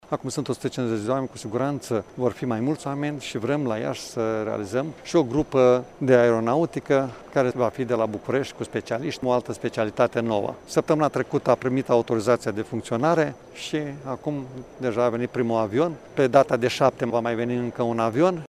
La rândul său, preşedintele Consiliului Judeţean Iaşi, Maricel Popa, a precizat că societatea a angajat 150 de oameni şi va avea colaborări inclusiv cu Universitatea Tehnică Gheorghe Asachi: